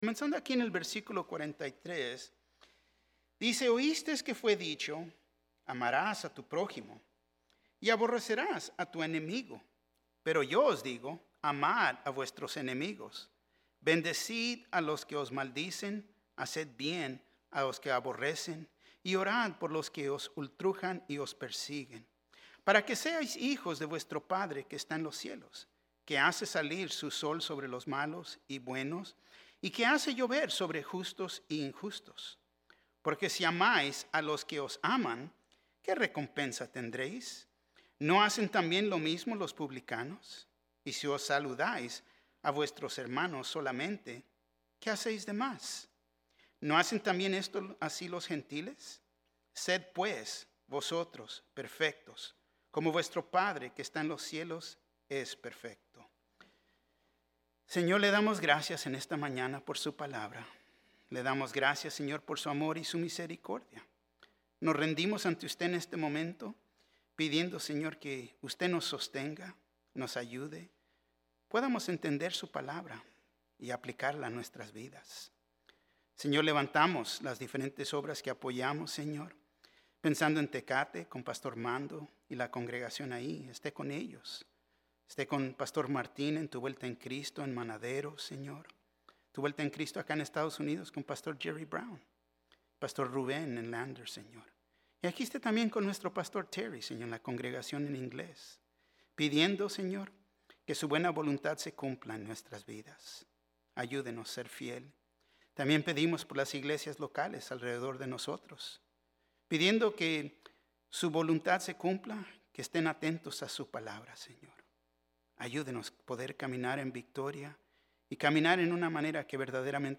Un mensaje de la serie "Liberados."